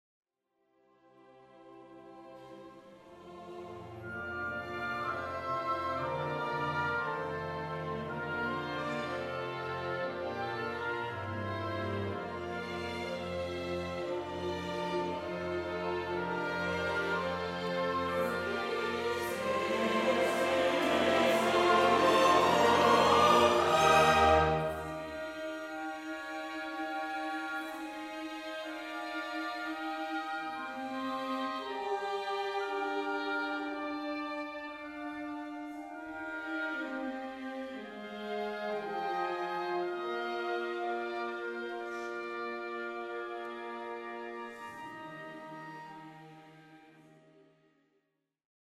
A nagy crescendonak nincs különösebb jelentősége, viszont ami utána van, az már ismerős lehet valahonnan.